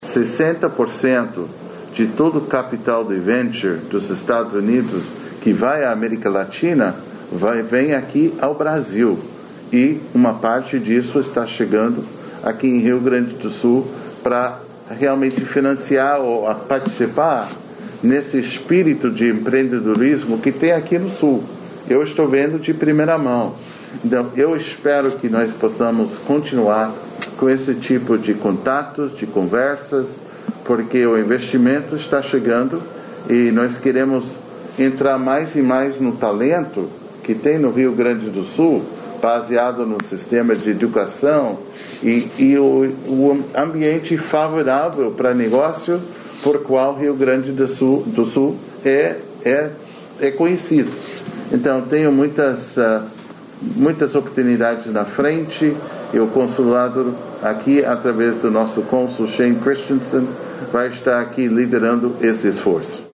COOPERACAO-RS-EUA_EMB-TODD-CHAPMAN.mp3